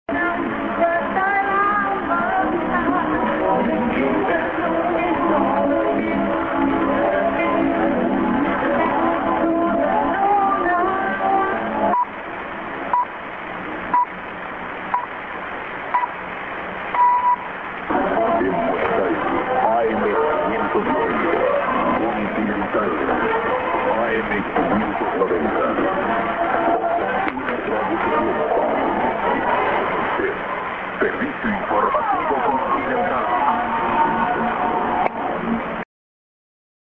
prog->TS->ID(man)-> アナウンスは単に"コンチネンタル"です。